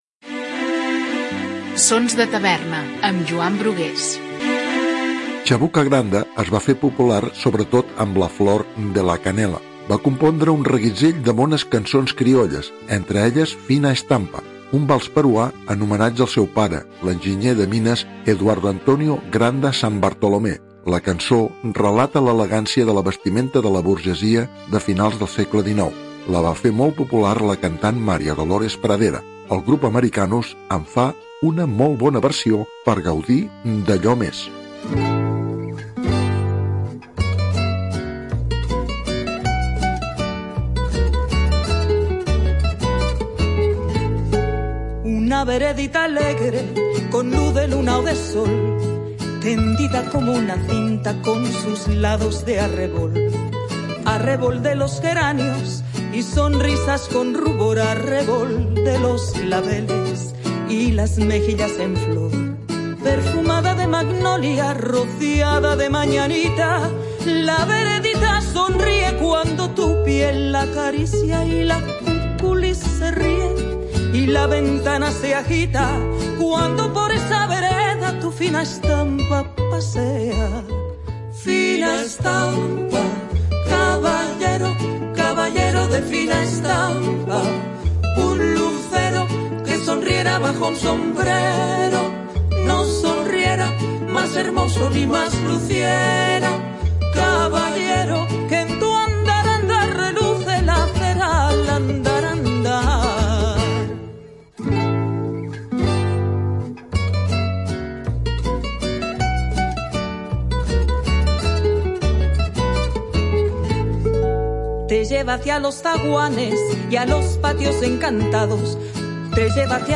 un vals peruà en homenatge al seu pare